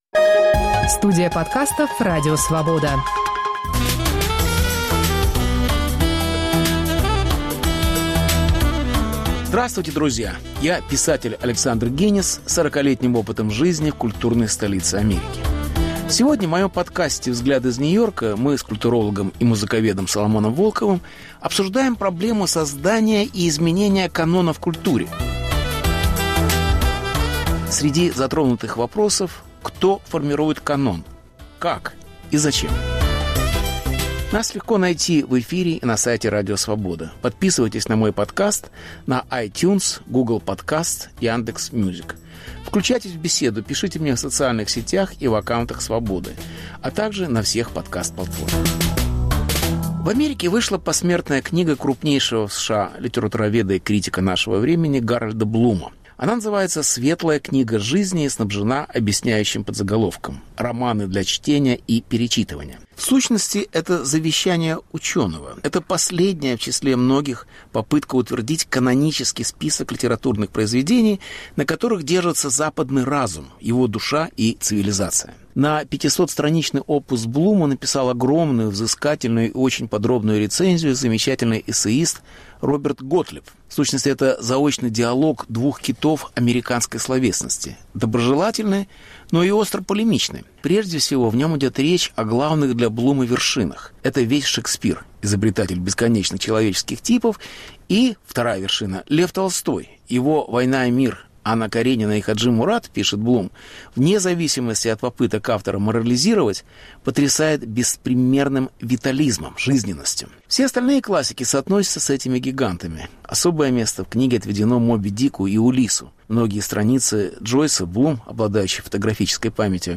Беседа с Соломоном Волковым о западной культуре и ее критиках. Повтор эфира от 15 марта 2021года.